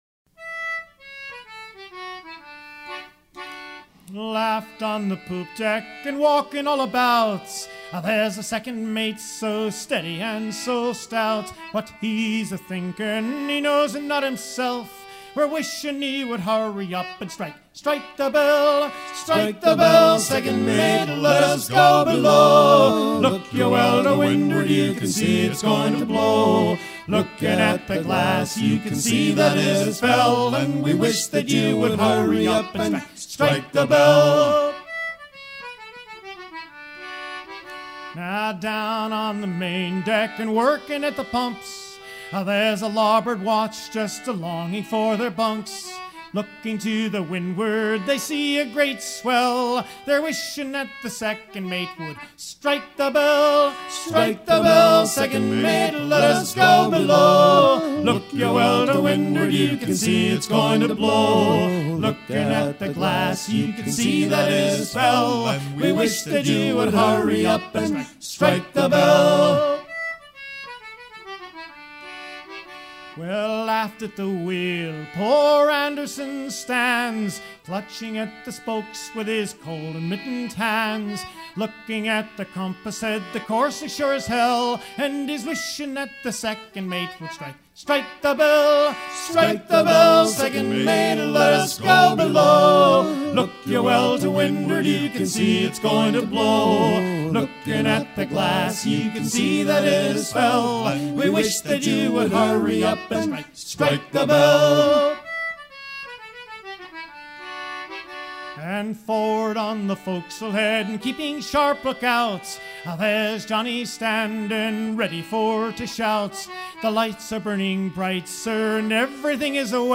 gestuel : à pomper
circonstance : maritimes
Pièce musicale éditée